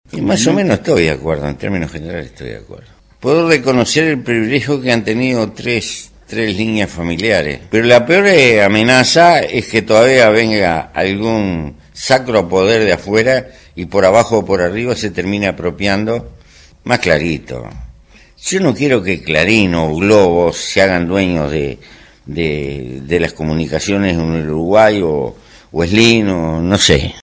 En entrevista con el programa No Toquen Nada de Océano FM, Mujica se refirió al desarrollo ferroviario, las mejoras en la enseñanza tecnológica y científica, la posibilidad de Topolansky como candidata a la intendencia de Montevideo, la ley de SCA y lo que considera su deuda pendiente: la reforma del Estado.